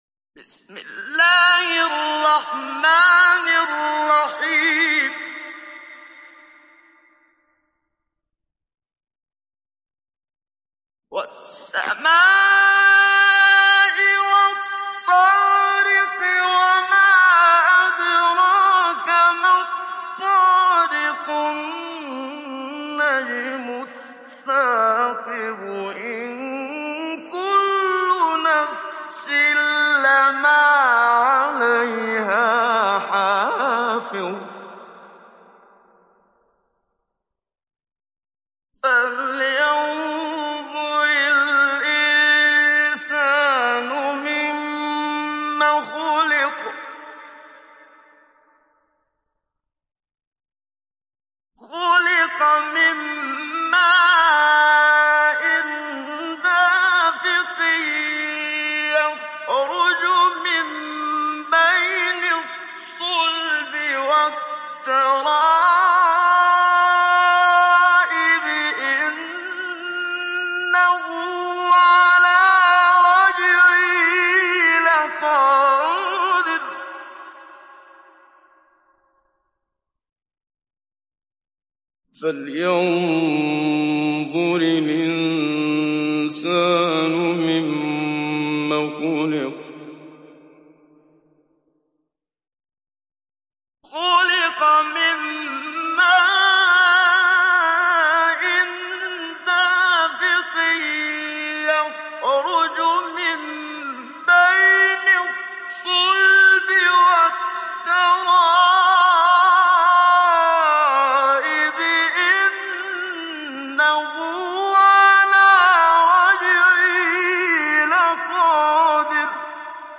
Surat At Tariq mp3 Download Muhammad Siddiq Minshawi Mujawwad (Riwayat Hafs)